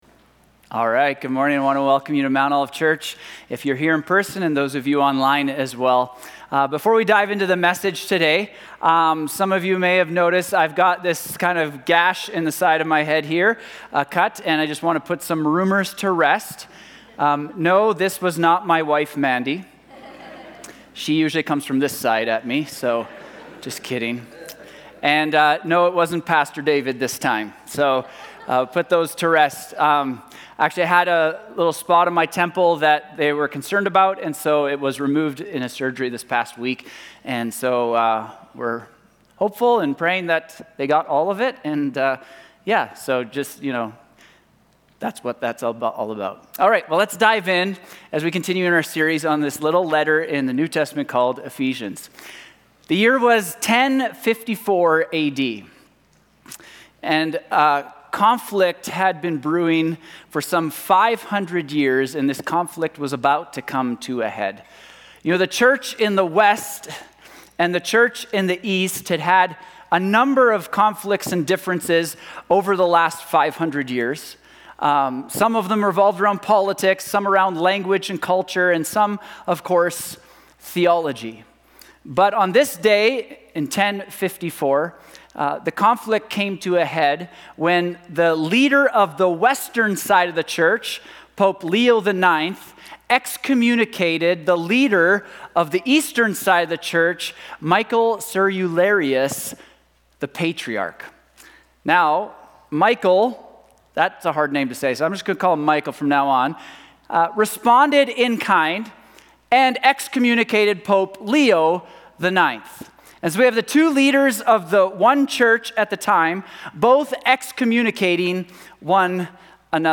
Sermons | Mount Olive Church